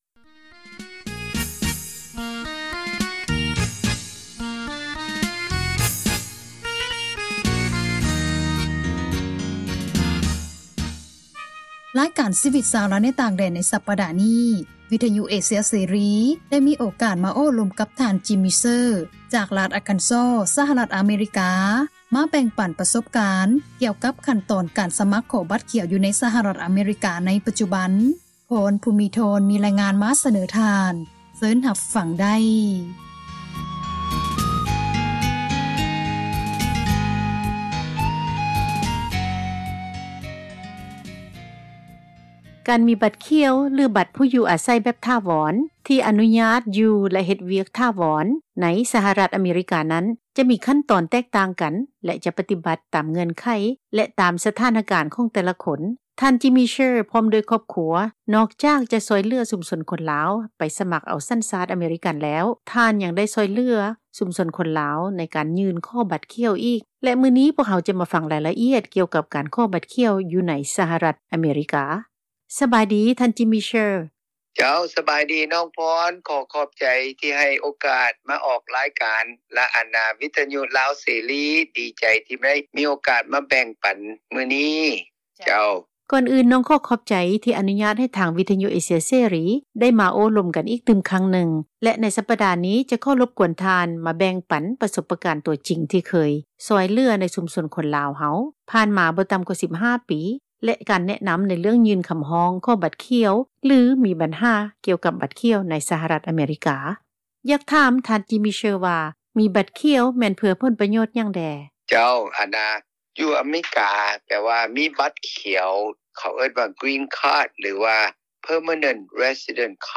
ຣາຍການ ຊີວິດຊາວລາວ ໃນຕ່າງປະເທດ ໃນສັປດານີ້ ວິທຍຸເອເຊັຽເສຣີ ໄດ້ມີໂອກາດ ມາໂອລົມ